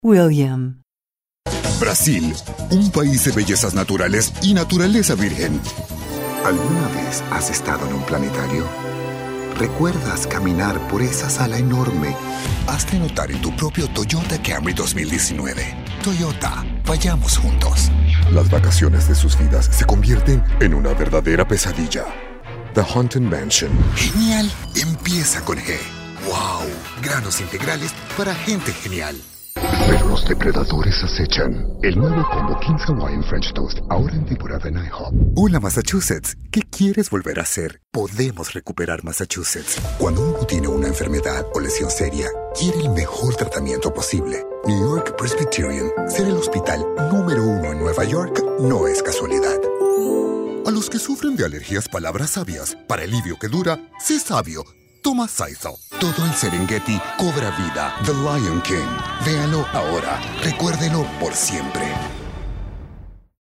Deep, resonant and full of emotion.